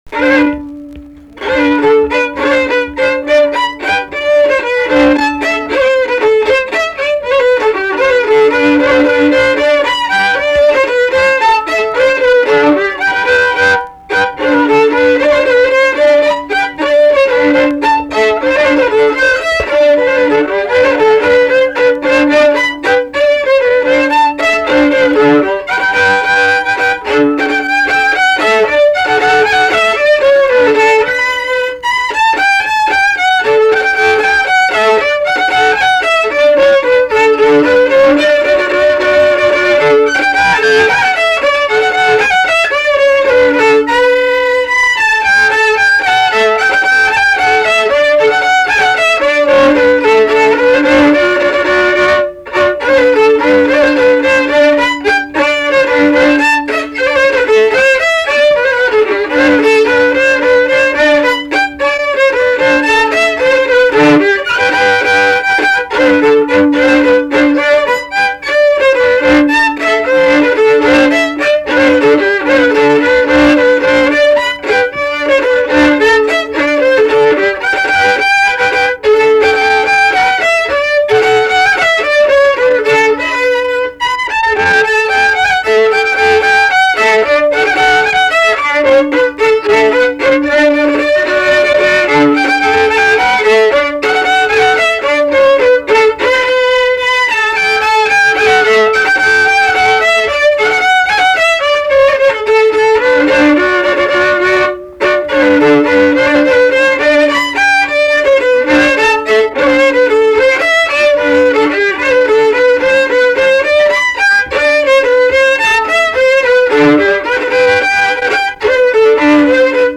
Valsas
šokis